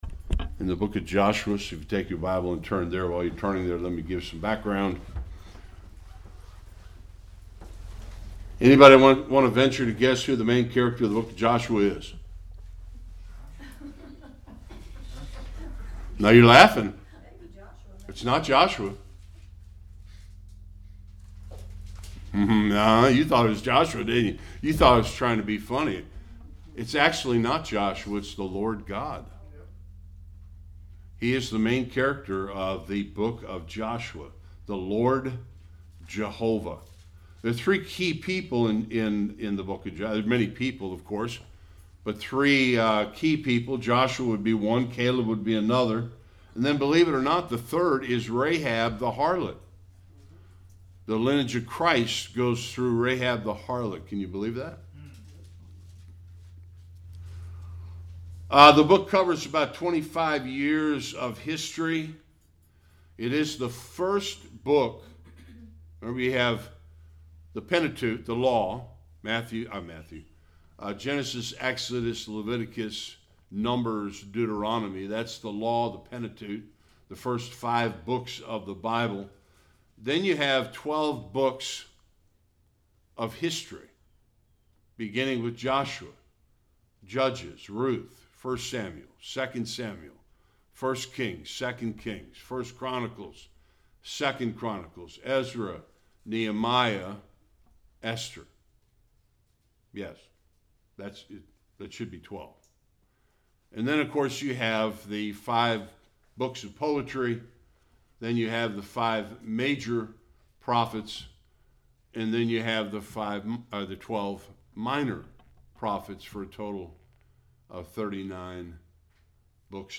Various passages Service Type: Sunday School Background information to begin our study of the book of Joshua.